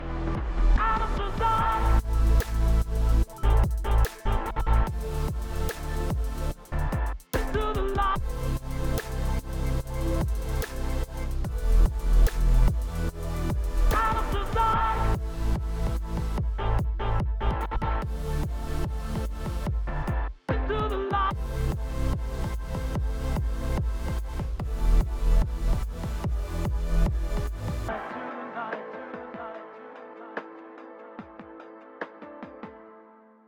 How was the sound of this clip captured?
Here are some things I am currently working on (the volume is a bit low because I have not mastered them):